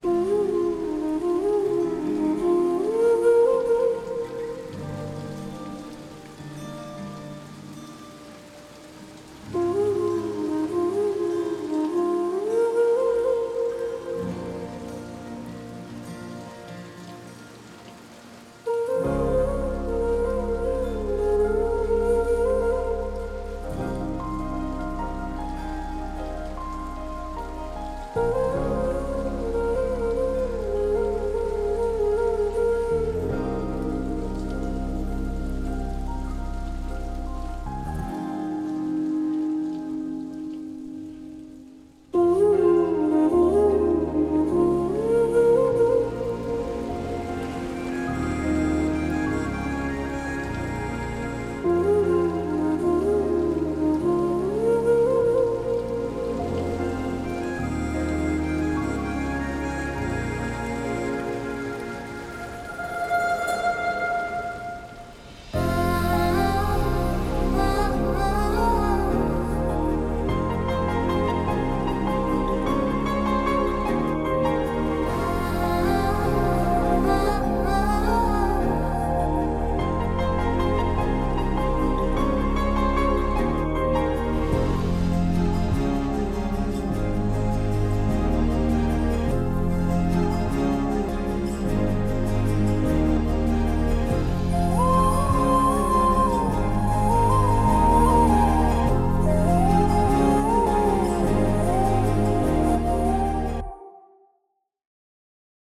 without dialogues